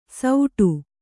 ♪ sauṭu